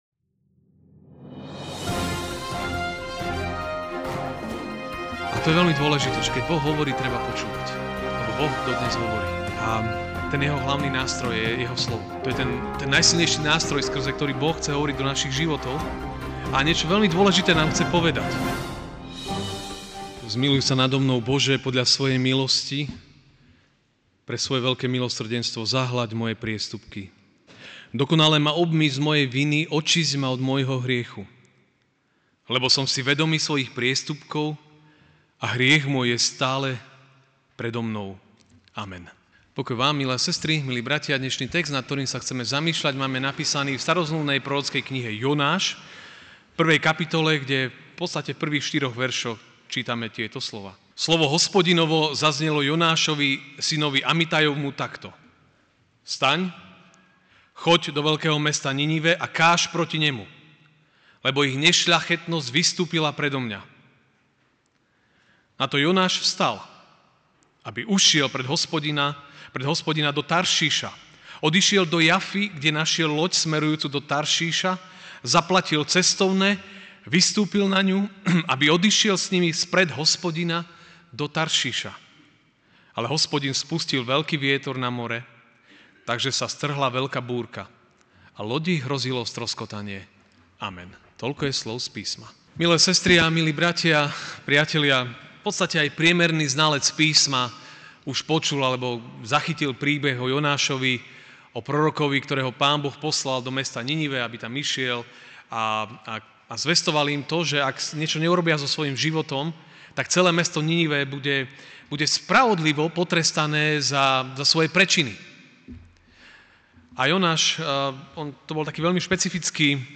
Ranná kázeň: Neposlušnosť búrky prináša! (Jonáš 1,1-4)